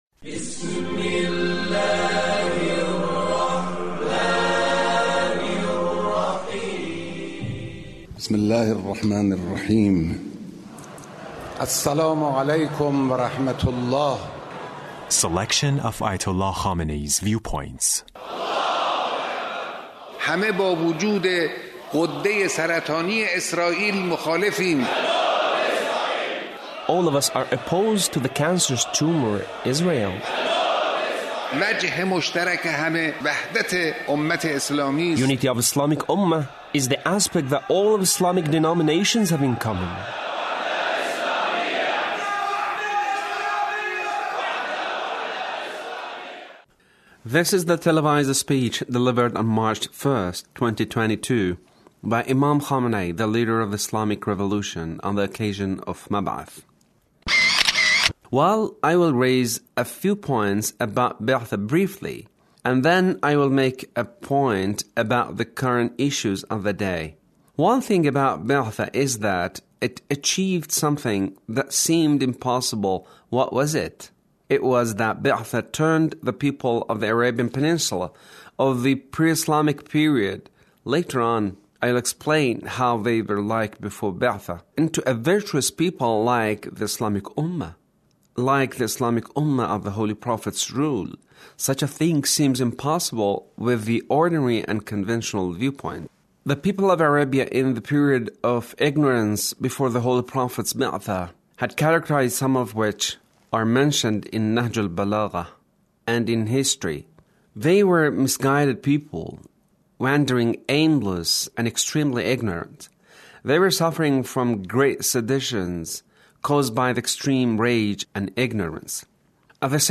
Leader's speech (1338)